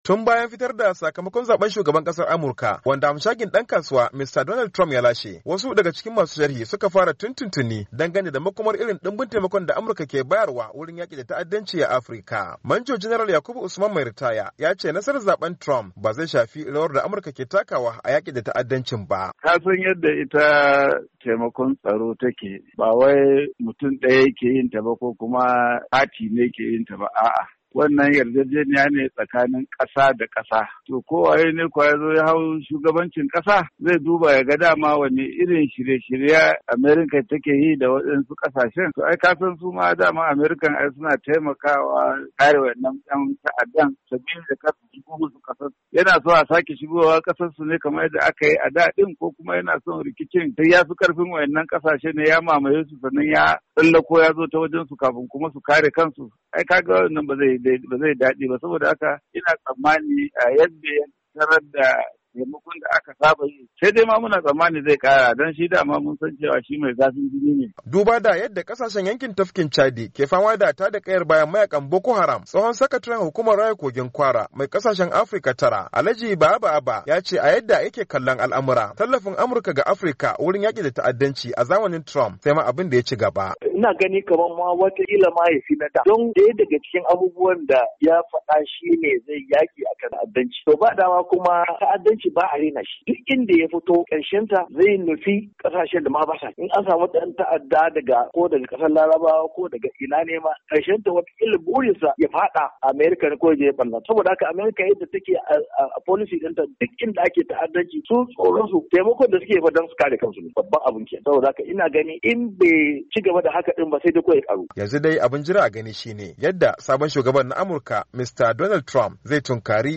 Ga rahoton